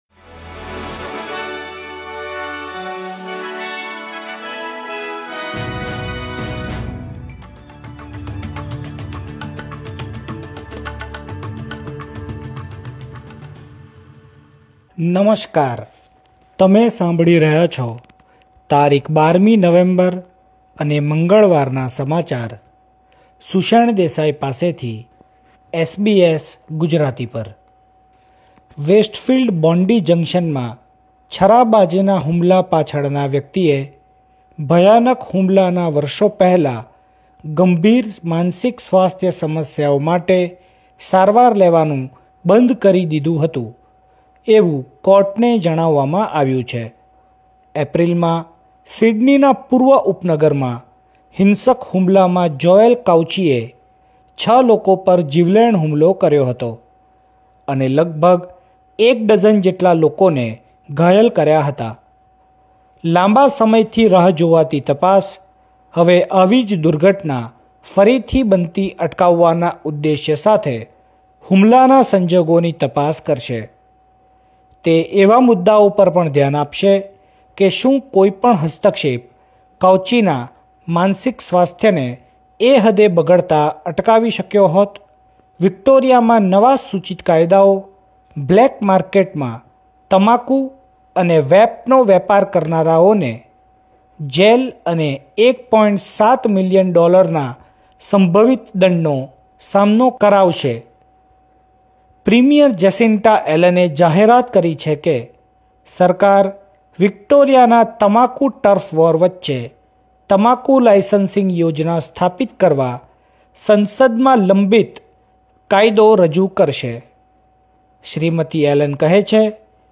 SBS Gujarati News Bulletin 12 November 2024